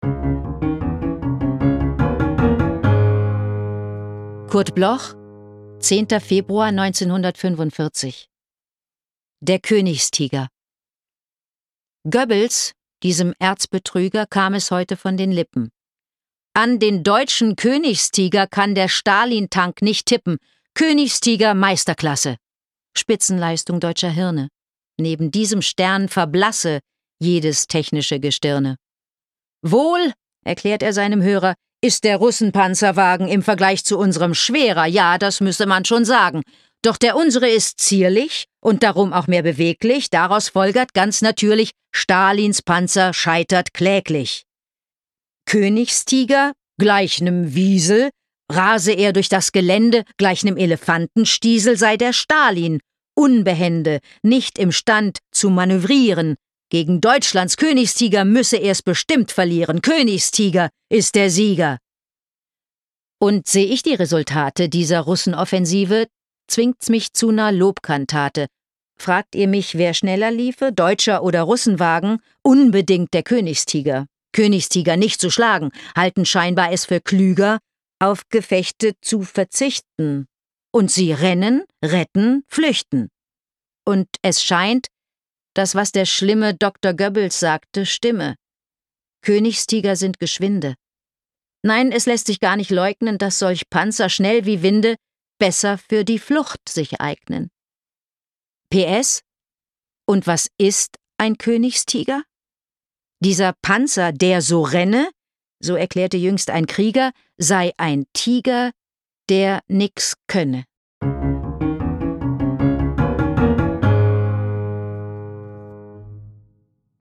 Aufnahme: Splendid Synchron GmbH, Köln · Bearbeitung/Musik: Kristen & Schmidt, Wiesbaden
Anke-Engelke-Der-Koenigstiger_raw_mit-Musik.m4a